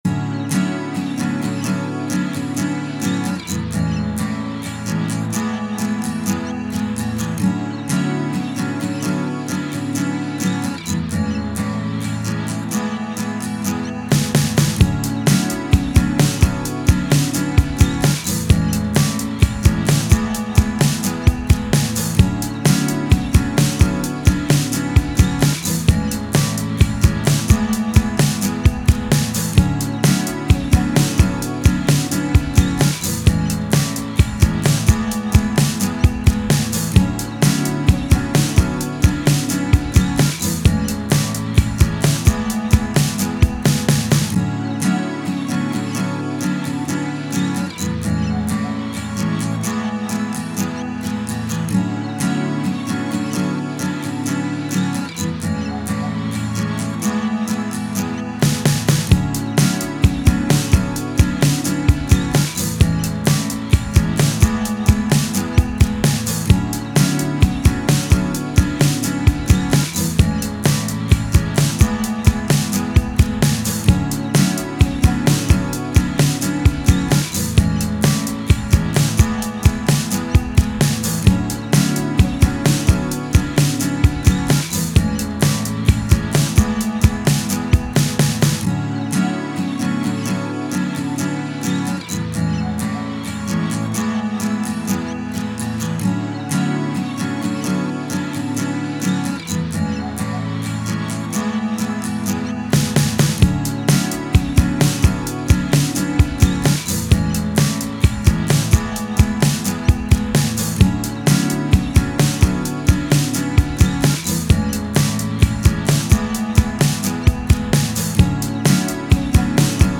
Indie Pop, Acoustic
A Major